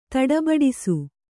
♪ taḍa baḍisu